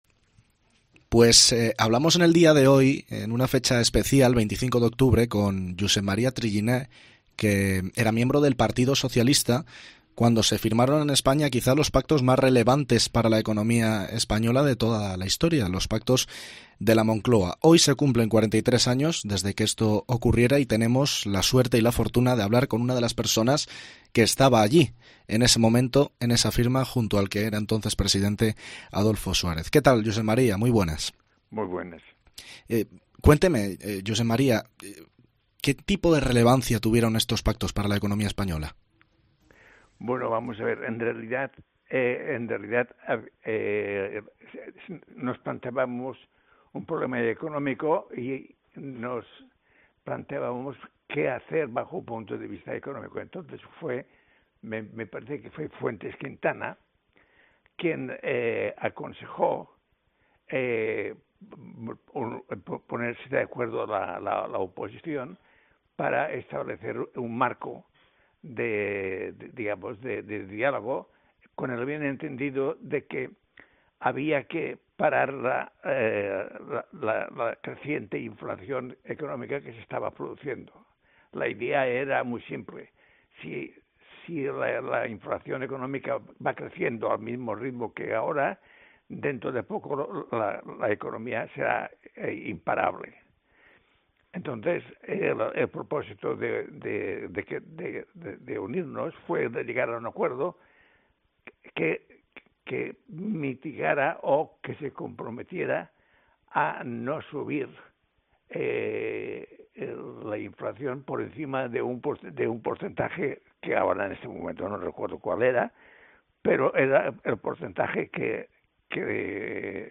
Se cumplen 43 años de los Pactos de la Moncloa. En COPE hablamos con Josep María Triginer, uno de los hombres que firmó aquel acuerdo: "Esa firma salvó nuestra Democracia"